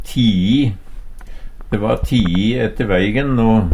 DIALEKTORD PÅ NORMERT NORSK tii tint Eksempel på bruk De va tii ette veigen no. Sjå òg teie (Nore) Høyr på uttala Ordklasse: Adverb Attende til søk